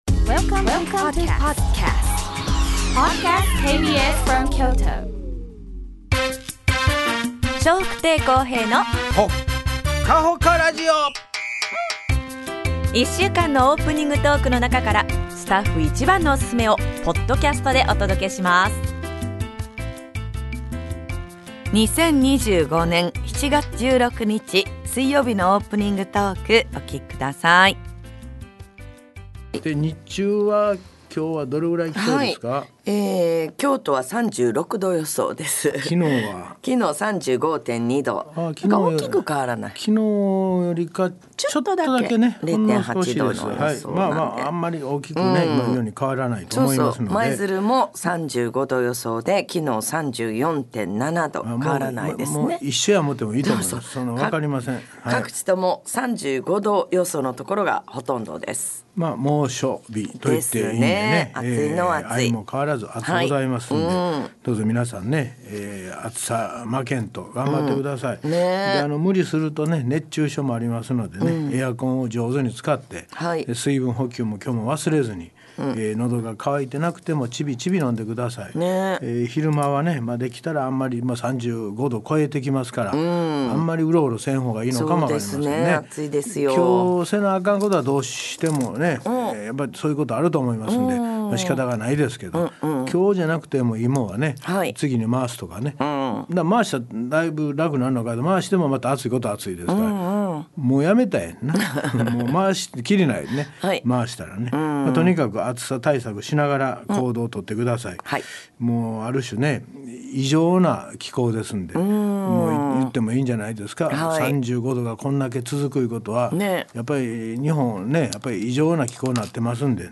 2025年7月16日のオープニングトーク